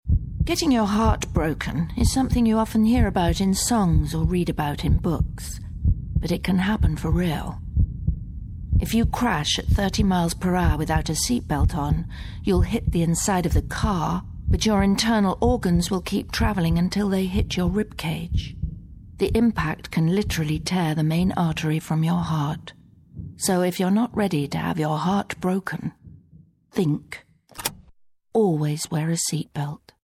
LISTEN: Listen to the THINK! radio advert